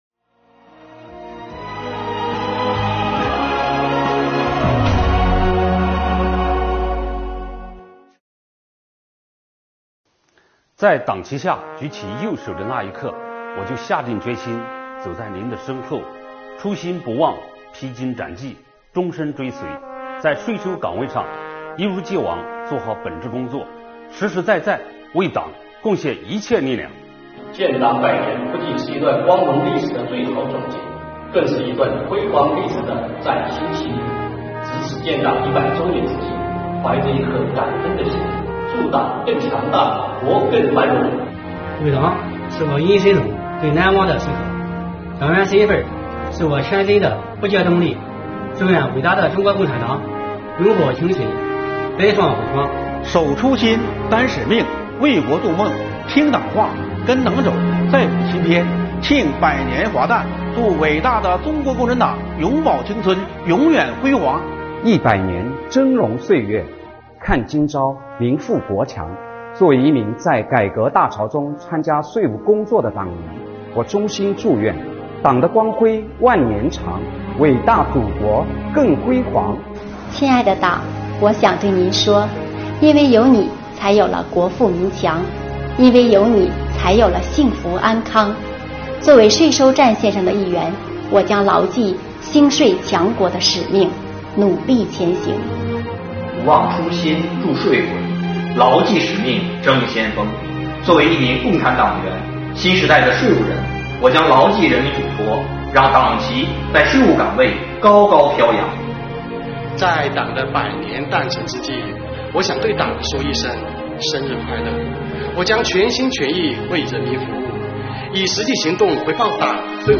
今天是建党100周年纪念日，税务系统广大党员干部面对镜头，为党送上诚挚的祝福。
中年党员干部们表示